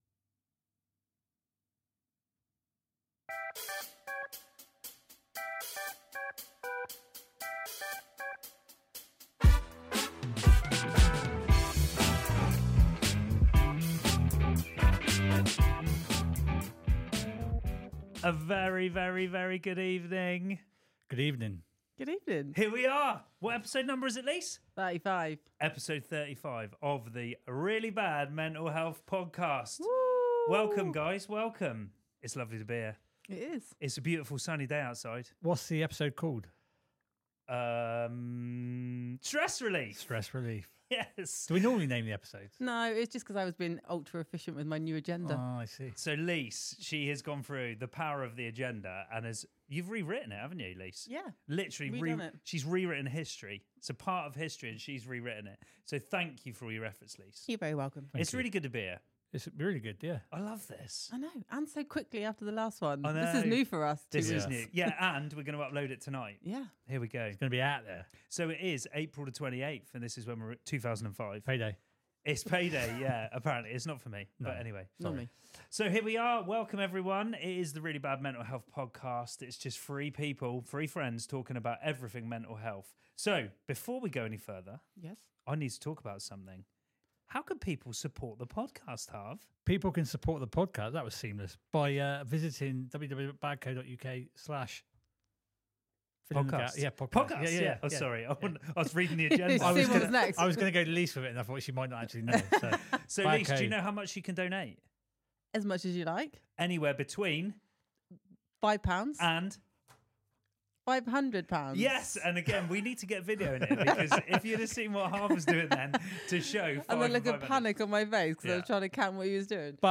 This week the team discuss Polish easter, fish (to all intents and purposes), films we may or may not have watched and of course, Gladiators. There's the usual chat around a particular focus and this week it's sleep, the lack thereof and potential treatments. There's discussion of what the BadCo team have been up to and what's upcoming too.